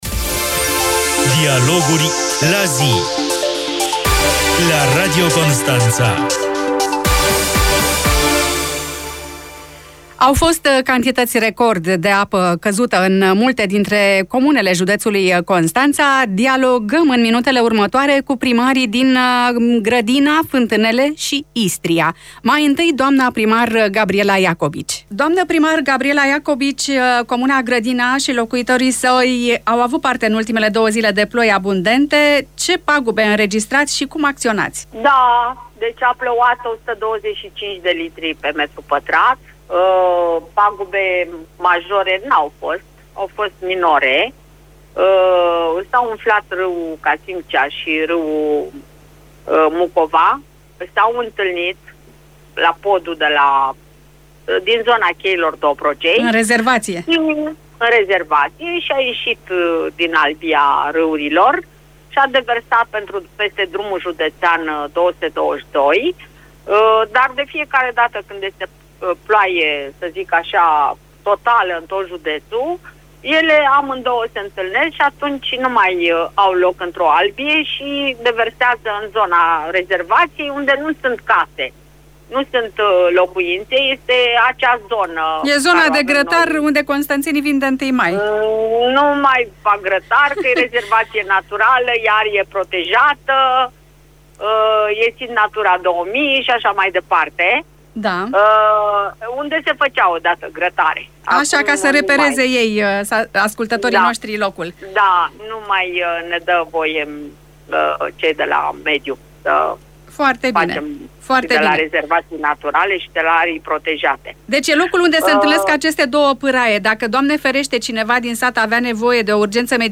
Invitații ediției au fost: Gabriela Iacobici, primarul comunei Grădina, Nicoleta Ciobanu, primarul comunei Fântânele, Mihai Ionescu, primarul comunei Istria